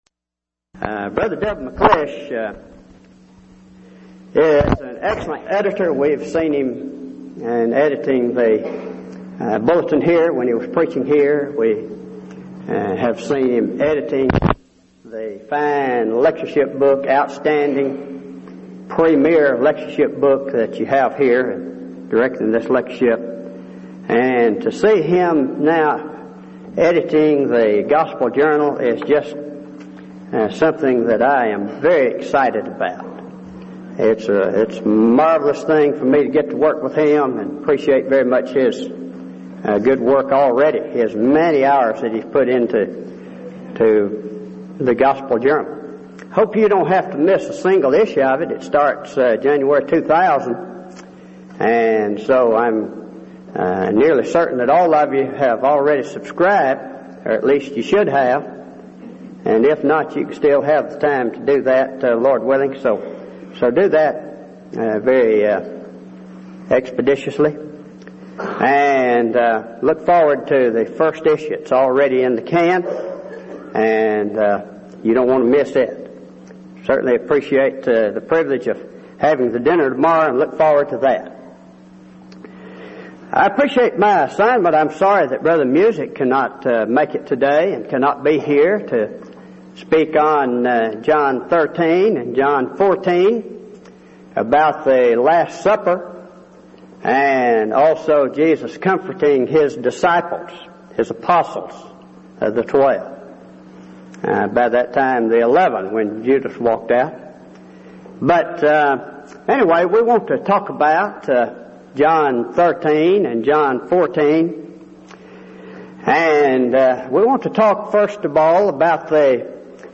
Event: 1999 Denton Lectures
lecture